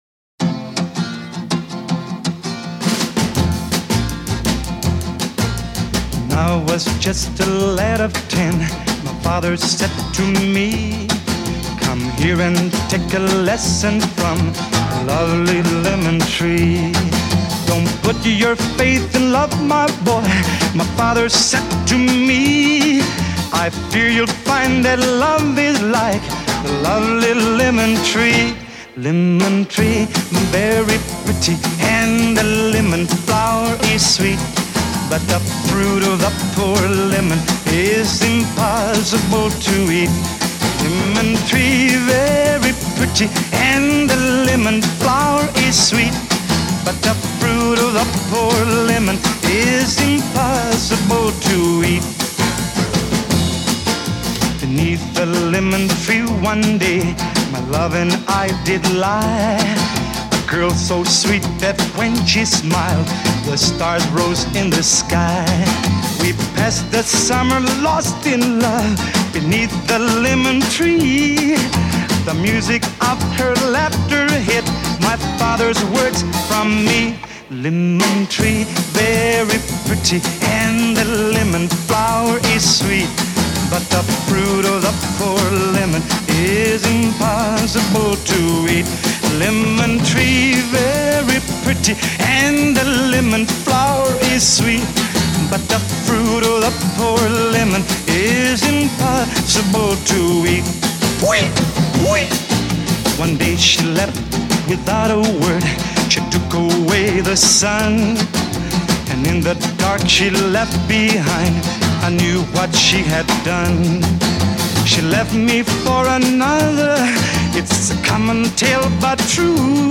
Но звучит ВПОЛНЕ ПРИЛИЧНО.